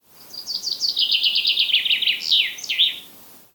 Art: Bokfink (Fringilla coelebs)
Sang
Lyder: Hannene synger om våren med en karakteristisk akselererende trille som kan beskrives som: “tje-tje-tje-…-tjenestepi”. Mange forveksler bokfinkens sang med løvsangerens, men bokfink synger kraftigere og har en tydelig avslutning på strofen – i motsetning til den dalende myke trillen til løvsanger.